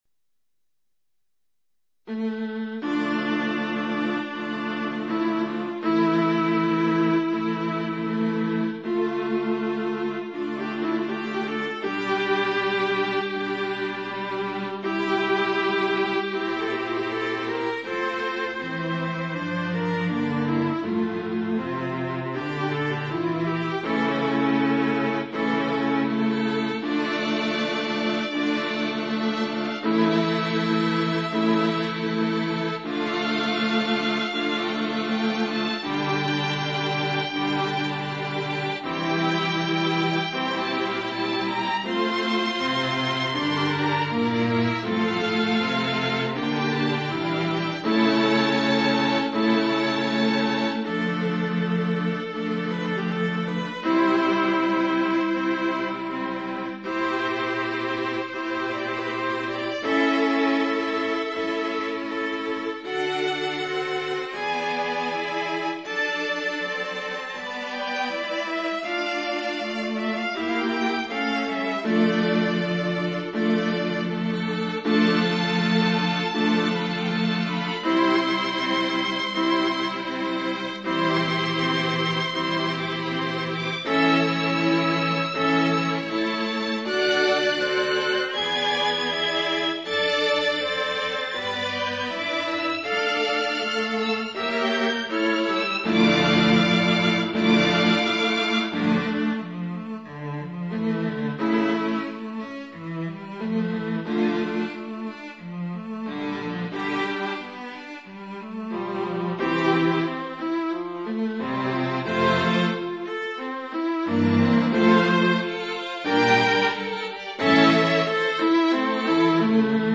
バイオリン×２、ヴィオラ×２、チェロ×２の弦楽器6台で演奏される大好きな室内楽です。重厚だけど、どこか希望に満ちた明るさのある曲で、覚悟を決めた男の花道にふさわしいのではと。